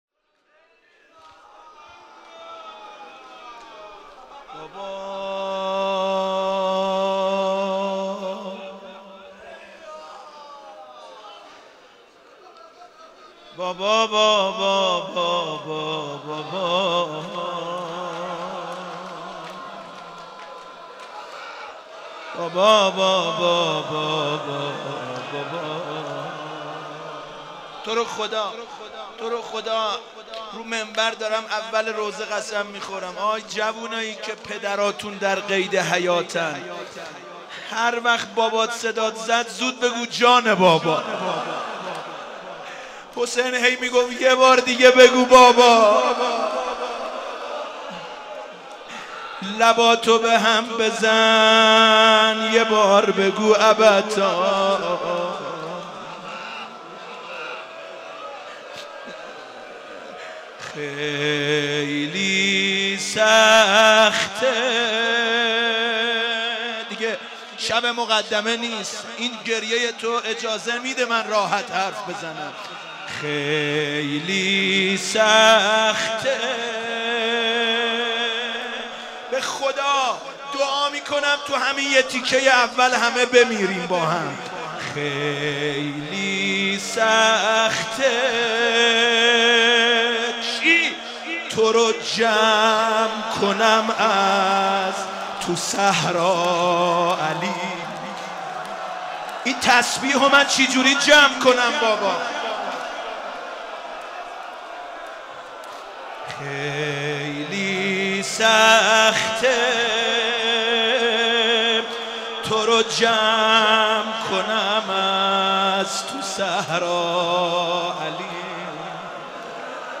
شب هشتم محرم97 - مسجد امیر - روضه